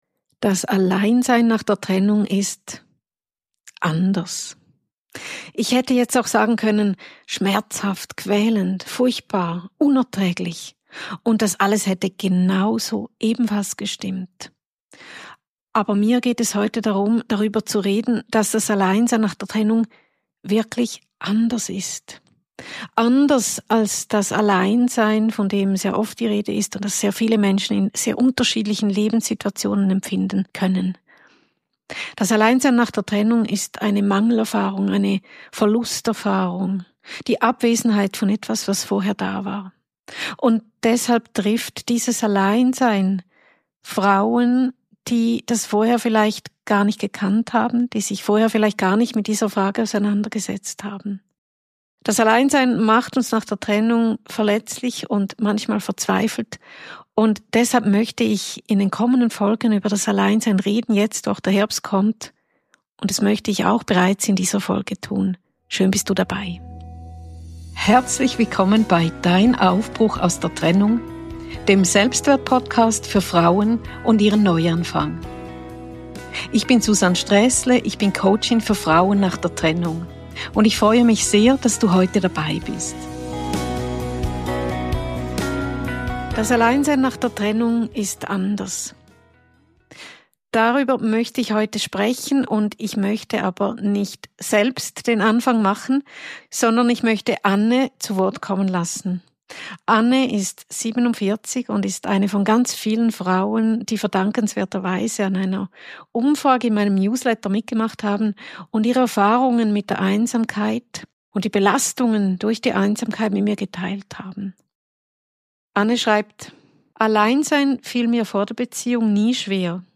In dieser Folge gebe ich den Stimmen von Frauen den Raum, die diese Erfahrung aktuell durchleiden und schildern, wie es ihnen dabei ergeht.